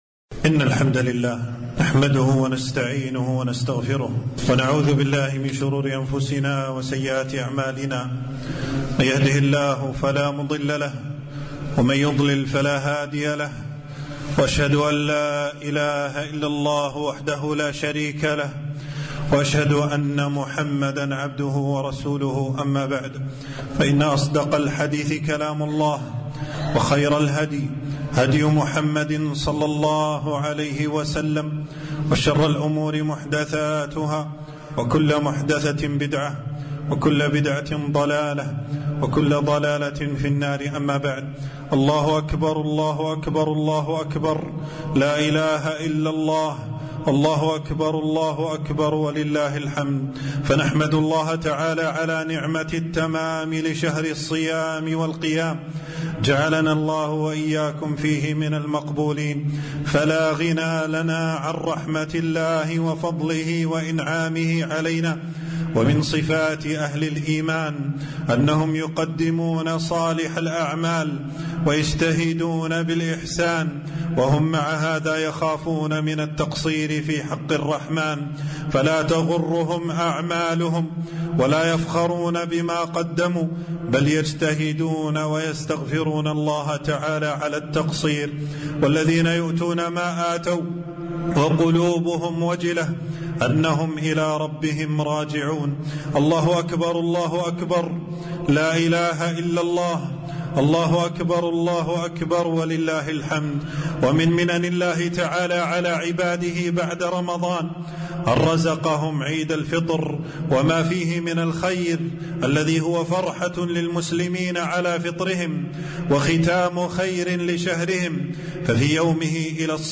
خطبة عيد الفطر 1443هـ